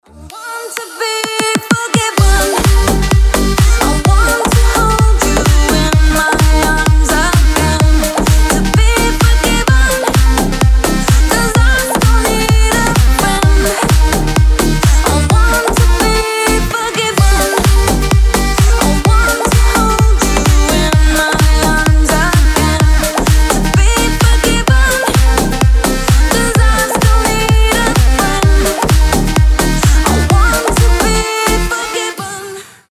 • Качество: 320, Stereo
remix
retromix
Cover
Старый хит в новом звучании